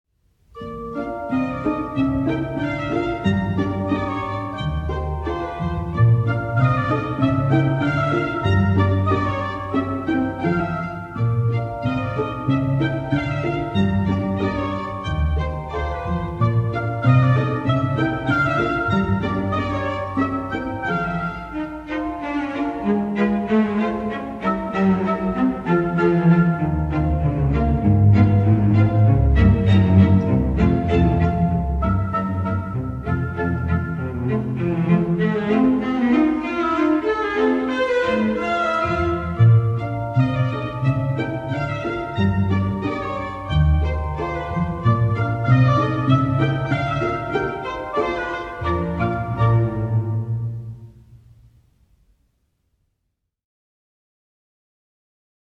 оркестровая версия